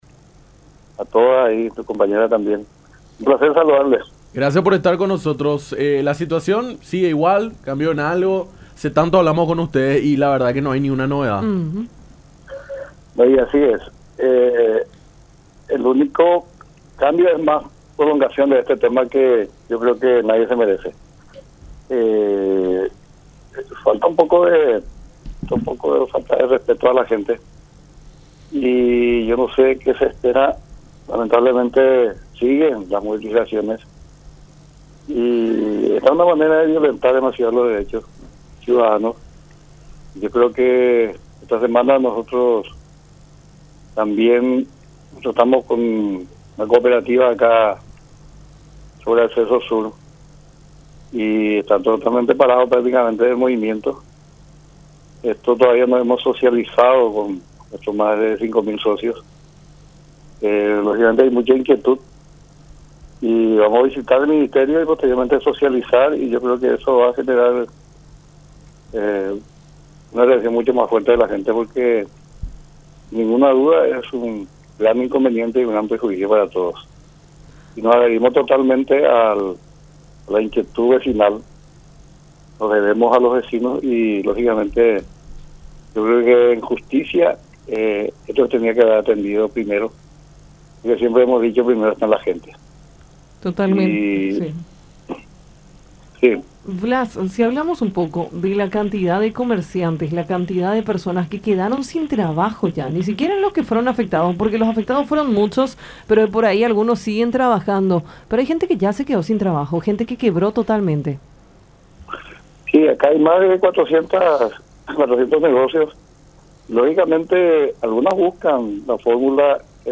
en diálogo con Nuestra Mañana por La Unión.